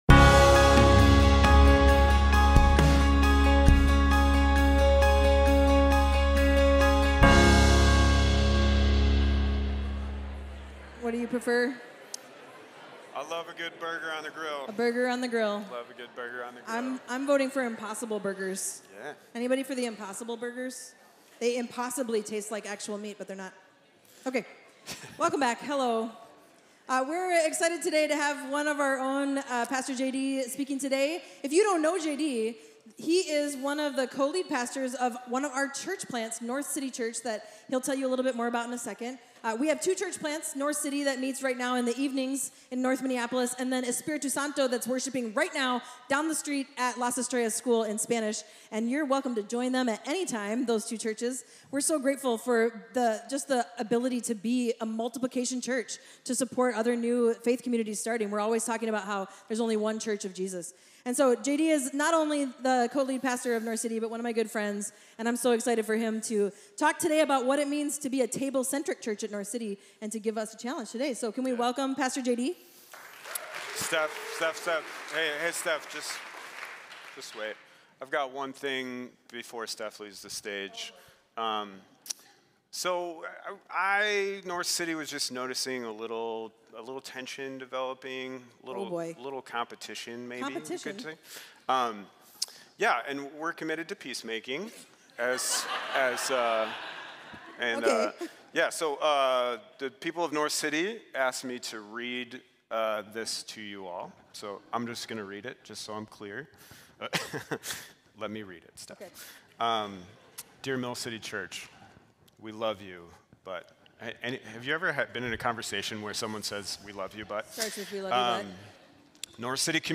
Mill City Church Sermons Apprenticing Jesus (IRL) Around the Table Jun 24 2024 | 00:38:44 1x 00:00 / 00:38:44 Subscribe Share RSS Feed Share Link Embed